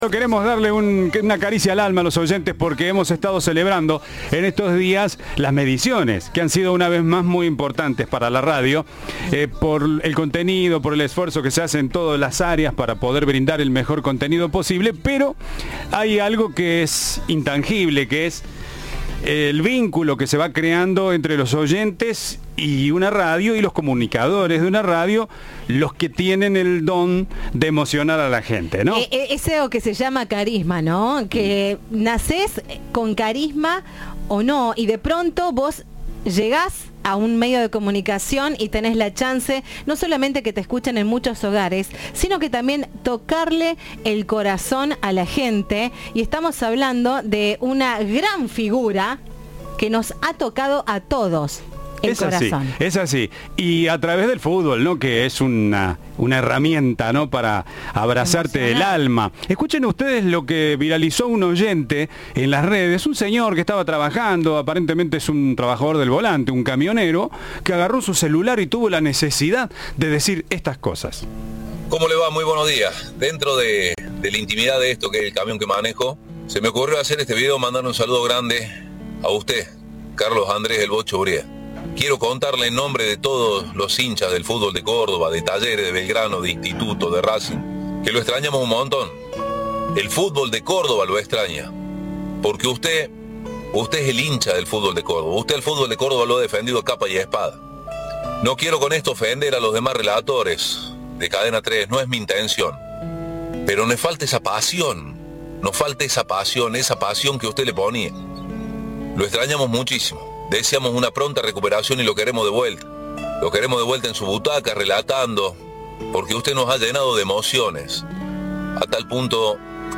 El histórico relator de Cadena 3 habló por primera vez en vivo de su recuperación tras ser intervenido del corazón a raíz de un video de apoyo de un camionero. Habló sobre su futuro y contó que está muy bien.